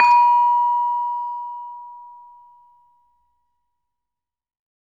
LAMEL A#4 -L.wav